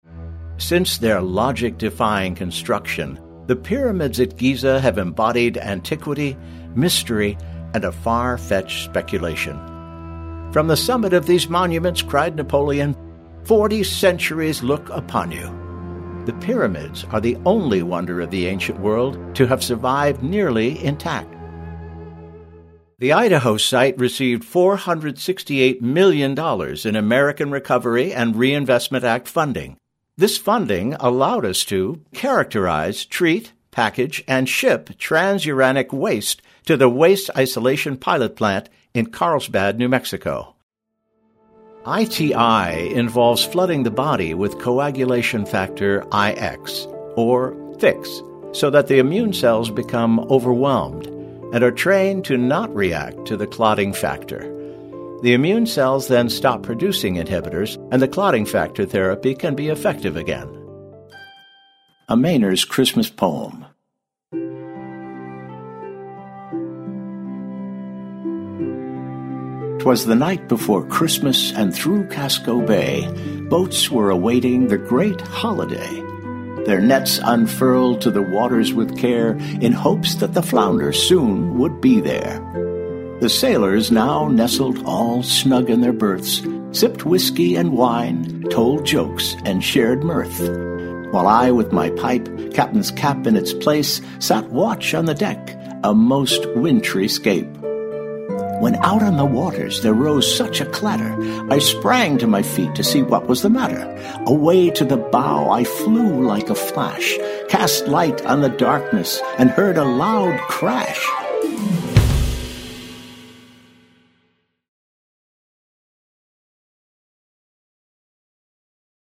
Narration
English - USA and Canada
Middle Aged